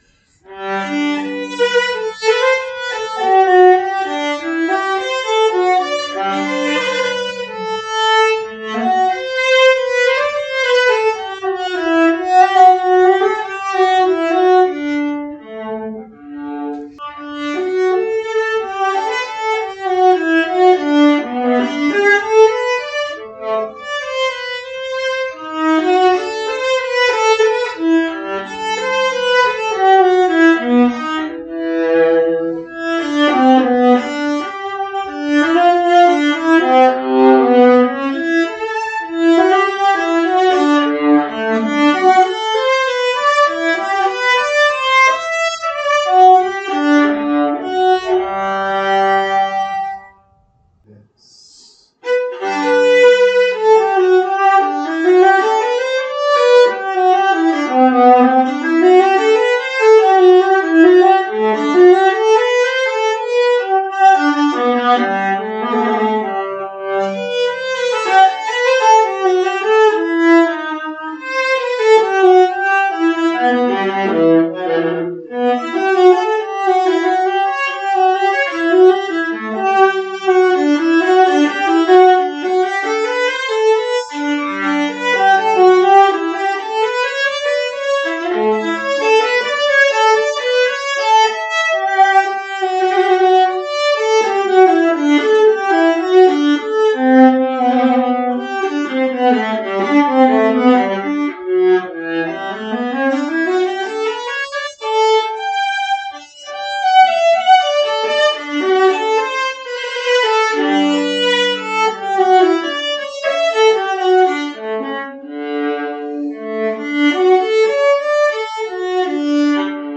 The resulting sound is dark, well balanced, even and with a strong projection.